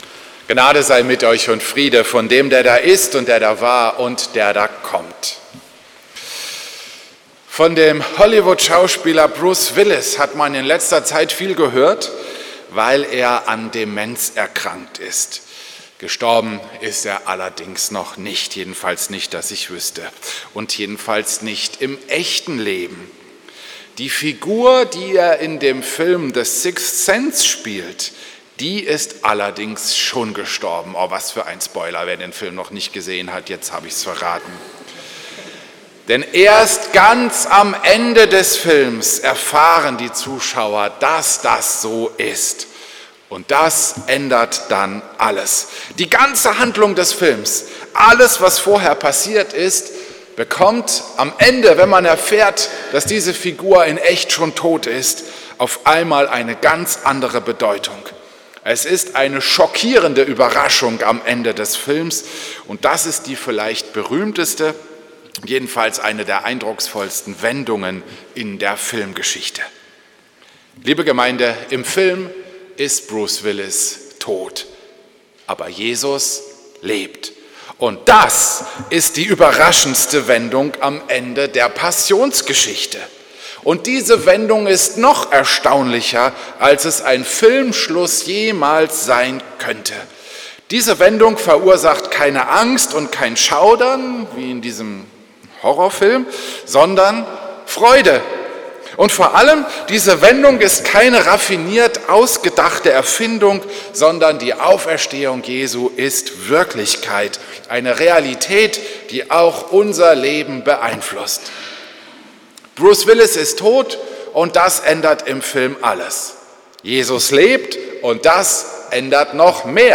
Predigt am Ostermontag